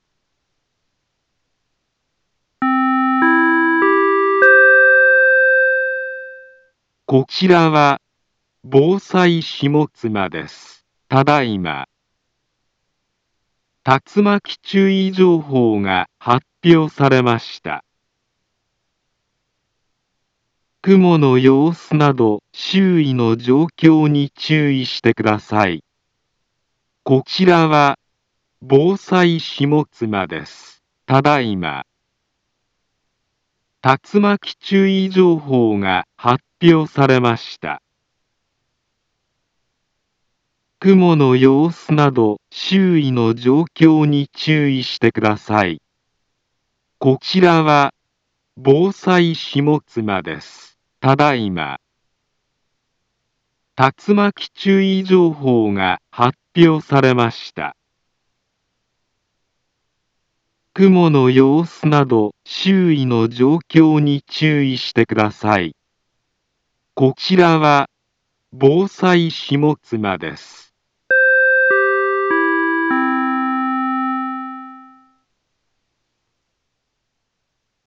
Back Home Ｊアラート情報 音声放送 再生 災害情報 カテゴリ：J-ALERT 登録日時：2023-09-08 18:49:39 インフォメーション：茨城県北部、南部は、竜巻などの激しい突風が発生しやすい気象状況になっています。